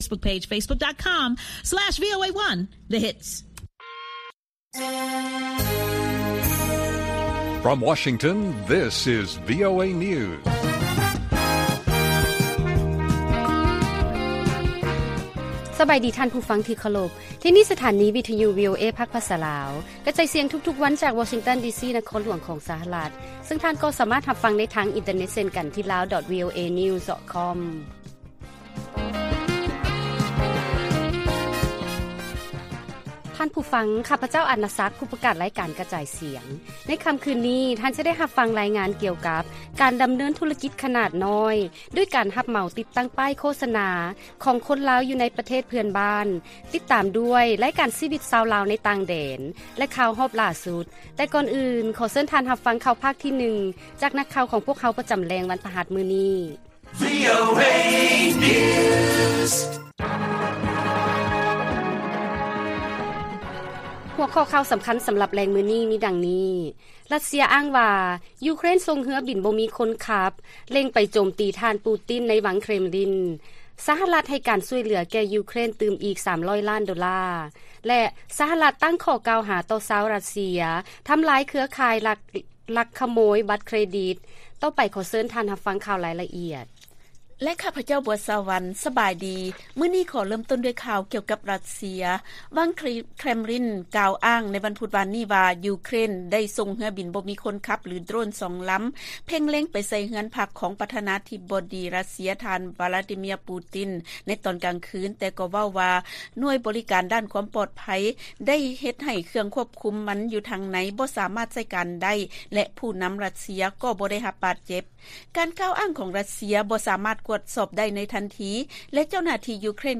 ລາຍການກະຈາຍສຽງຂອງວີໂອເອ ລາວ: ຣັດເຊຍອ້າງວ່າ ຢູເຄຣນ ສົ່ງເຮືອບິນ ບໍ່ມີຄົນຂັບ ເລັງໄປໂຈມຕີ ທ່ານປູຕິນໃນວັງເຄຣມລິນ